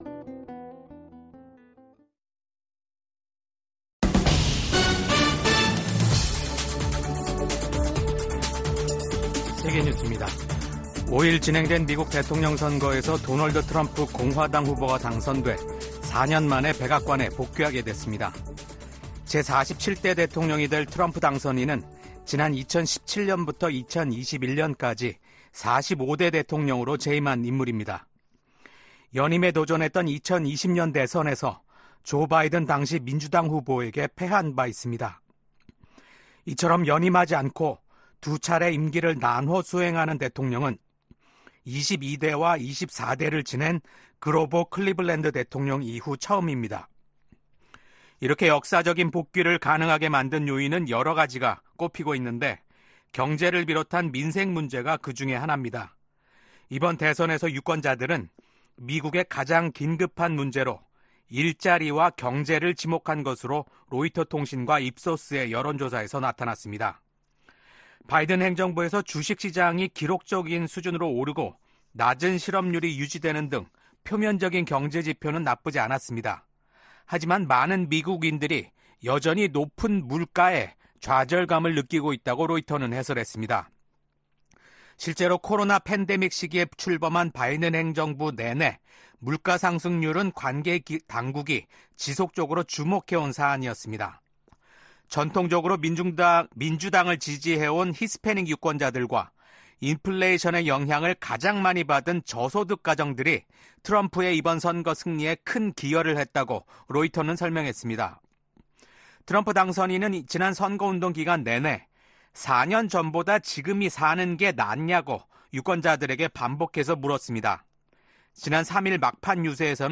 VOA 한국어 아침 뉴스 프로그램 '워싱턴 뉴스 광장' 2024년 11월 7일 방송입니다. 2024년 미국 대선은 공화당 후보인 도널드 트럼프 전 대통령이 민주당 후보인 카멀라 해리스 부통령을 크게 앞서면서, 47대 대통령에 당선됐습니다.